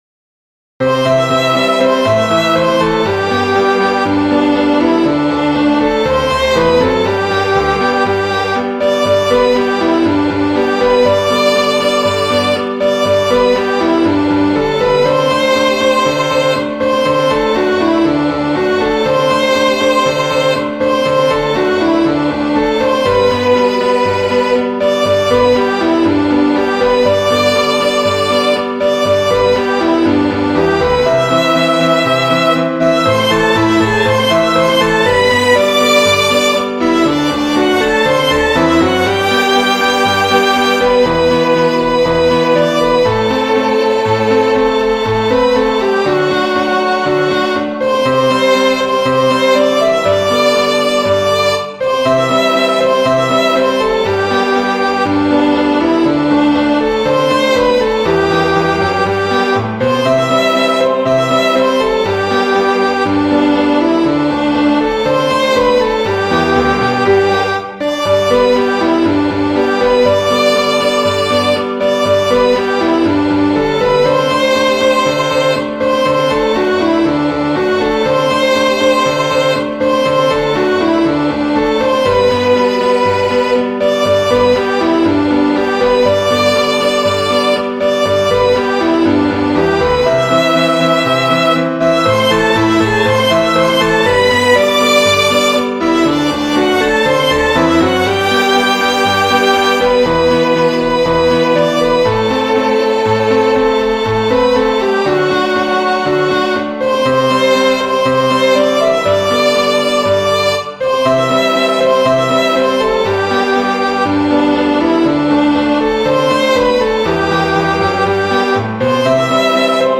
3  MARCHAS POPULARES para 2026